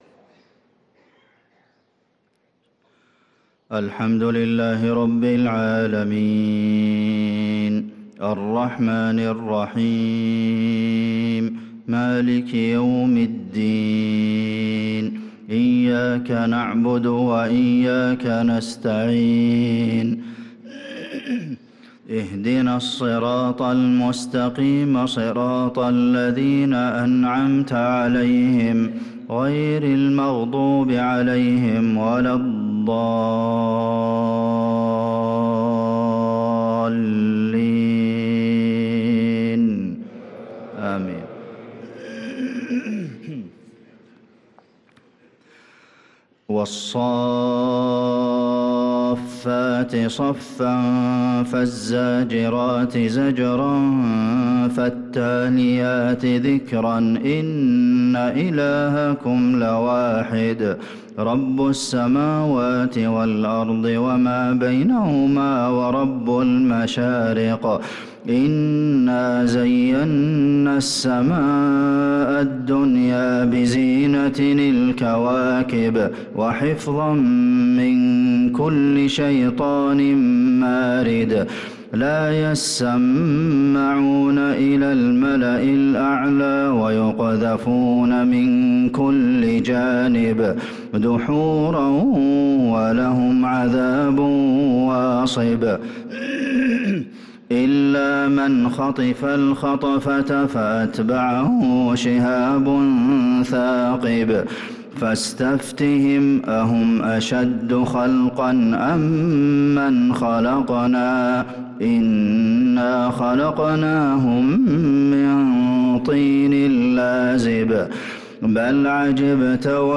صلاة الفجر للقارئ عبدالمحسن القاسم 25 رجب 1445 هـ
تِلَاوَات الْحَرَمَيْن .